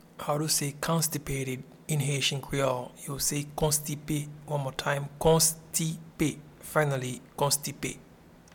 Pronunciation and Transcript:
Constipated-in-Haitian-Creole-Konstipe.mp3